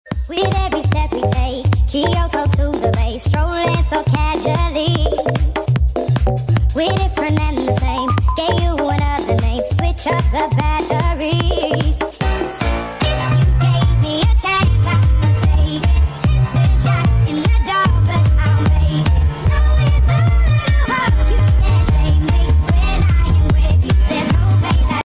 Soundcloud bass boosted part 3 sound effects free download